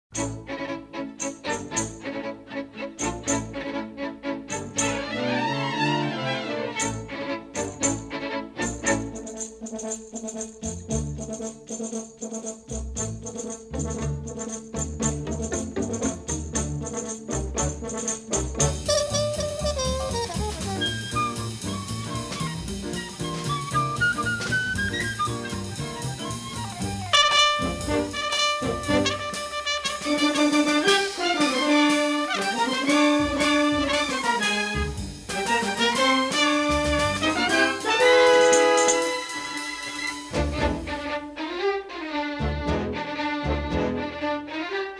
are presented from clean-sounding mono 1/4" tape.